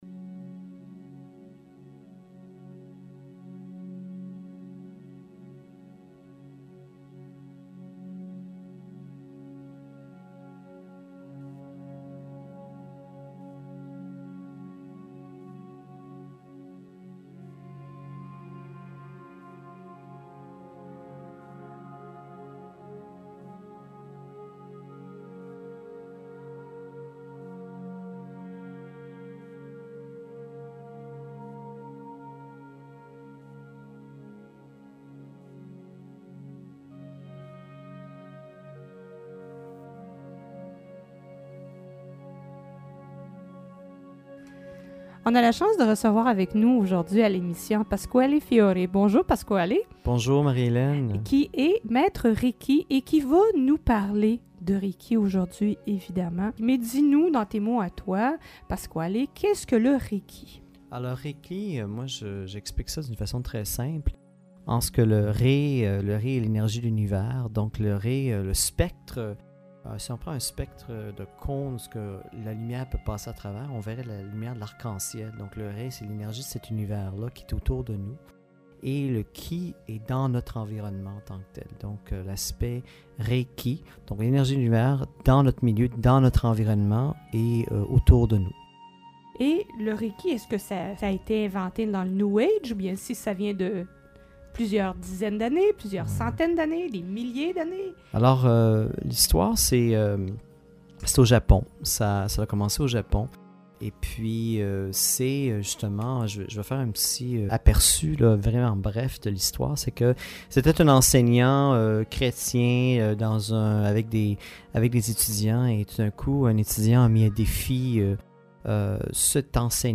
interviewed on the radio